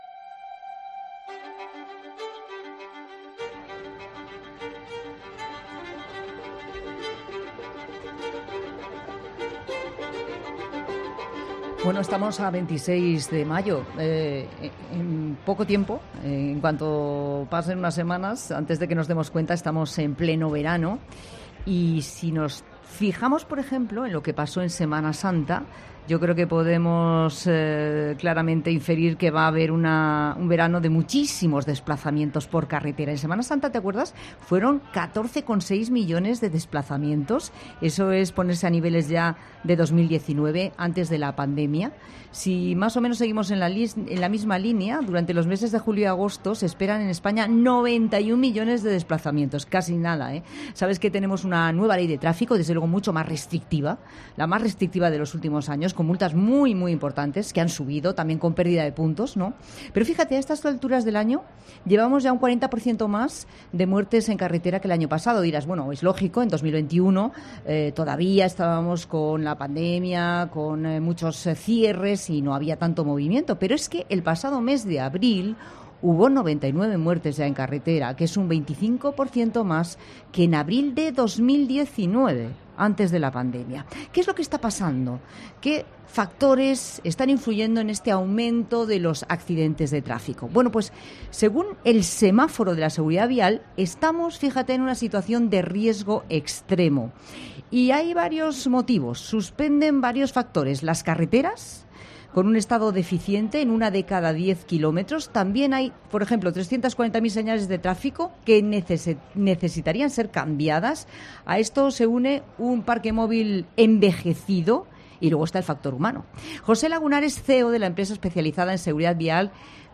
La Tarde de COPE charla